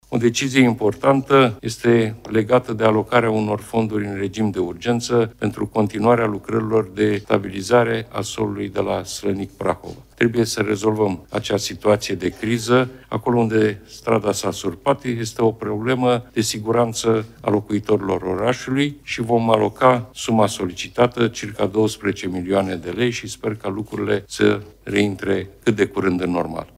Premierul Marcel Ciolacu, în ședința de Guvern de astăzi: „Vom aloca suma solicitată, circa 12 milioane de lei, și sper ca lucrurile să reintre cât de curând în normal”